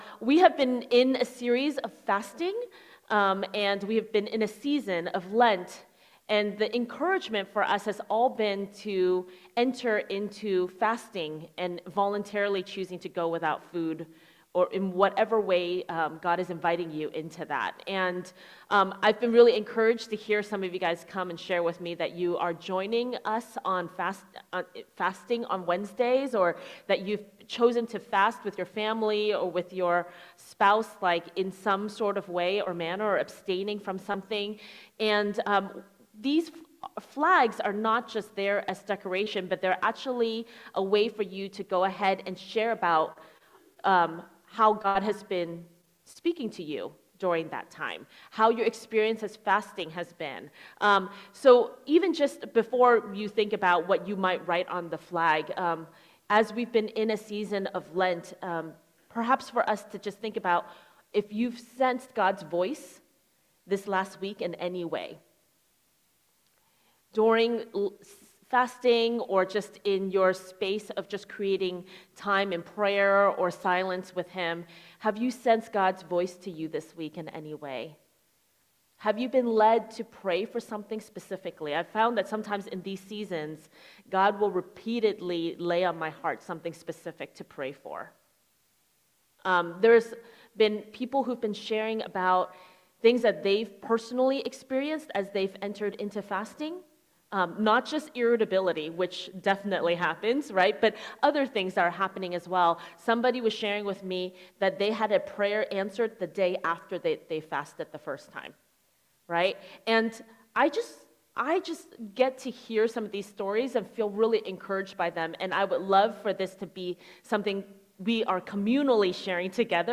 Sermons | Missio Community